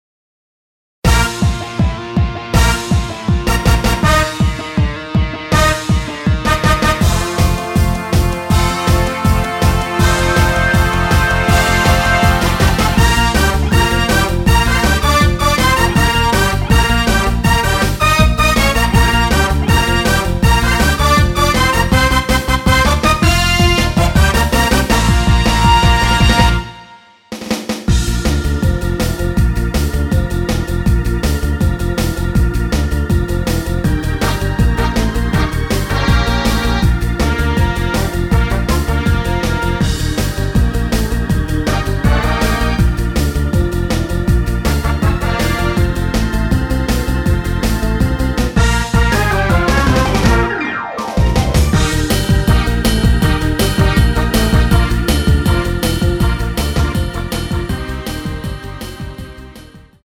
여성분이 부르실수 있는키의 MR 입니다.
Bb
앞부분30초, 뒷부분30초씩 편집해서 올려 드리고 있습니다.
중간에 음이 끈어지고 다시 나오는 이유는